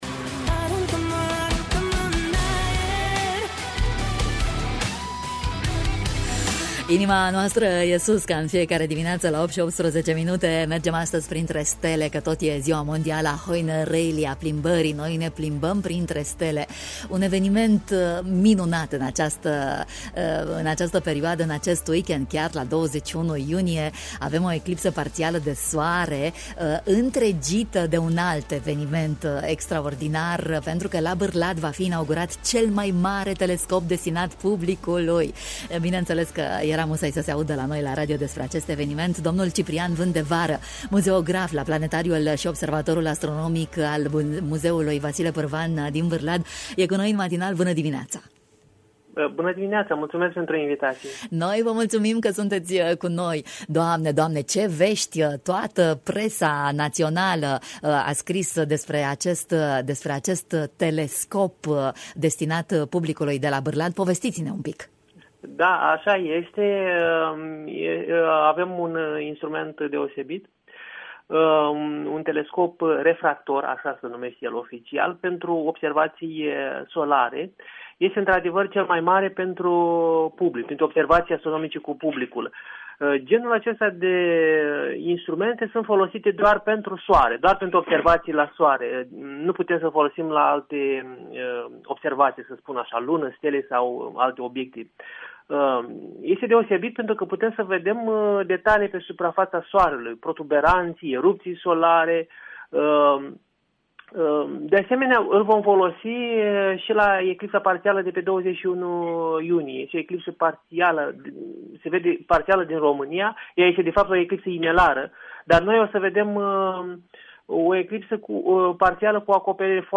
ne-a introdus în lumea fascinantă a soarelui în matinalul Bună Dimineaţa: